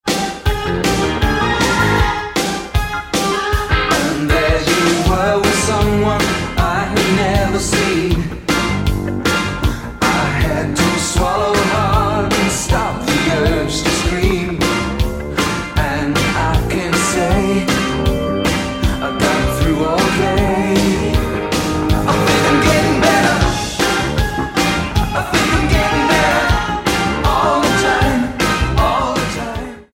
guitar, keyboards, percussion, vocals
bass
drums